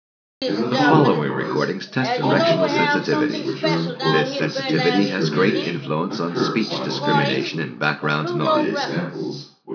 Aquí escucharemos a un hombre hablando en un ambiente con conversaciones de fondo, grabado mediante diferentes configuraciones de micrófonos.
La primera grabación corresponde a un único micrófono.
Single array microphone.wav